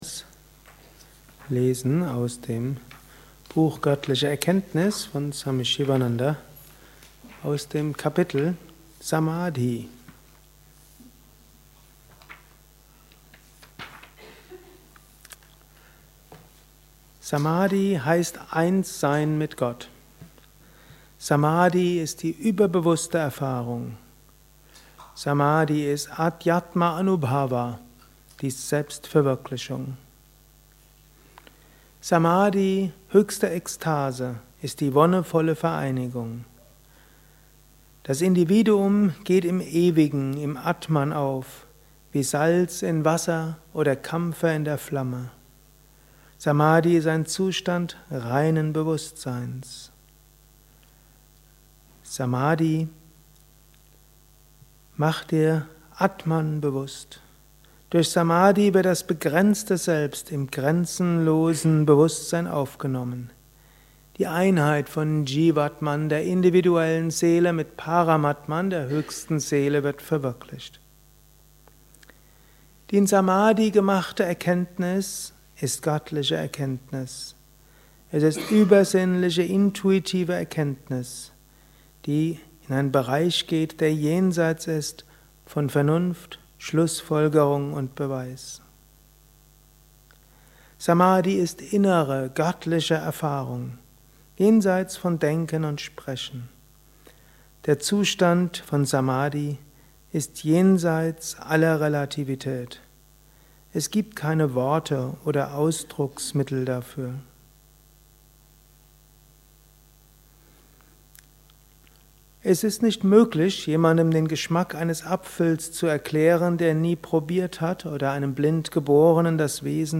Jeden Tag ein 5-10 minütiger Vortrag über Yoga, Meditation und spirituelles Leben im Alltag.